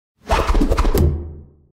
Звуки вращения
На этой странице собраны разнообразные звуки вращения: от легкого шелеста крутящихся лопастей до мощного гула промышленных механизмов.
Вращение ножа в полете